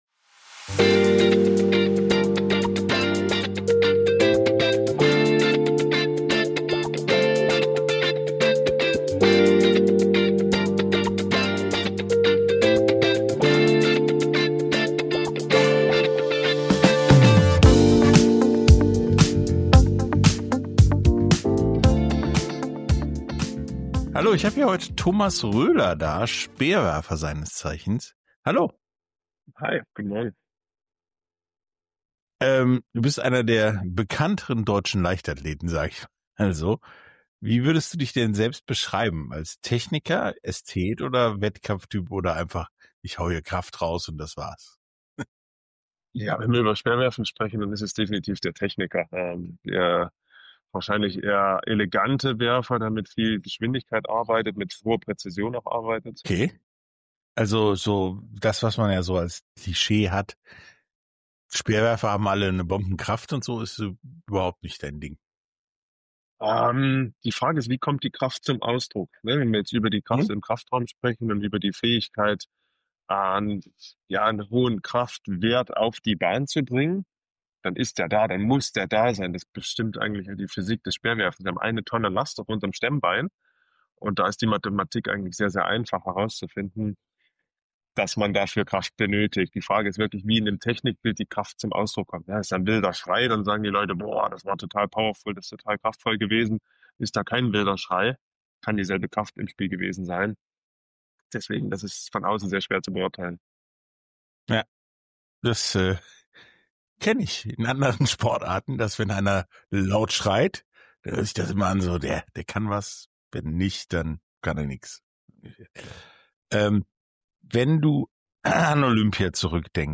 Sportstunde - Interview mit Thomas Röhler, Leichtathletik-Speerwerfen - Olympiasieger ~ Sportstunde - Interviews in voller Länge Podcast